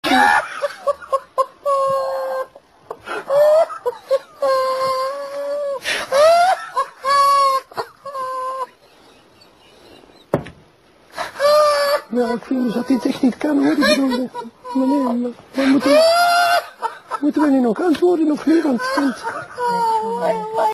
Play Apresentador Ri - SoundBoardGuy
Play, download and share Apresentador ri original sound button!!!!
hahahaha_lH0klaN.mp3